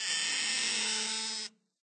wood_creak.ogg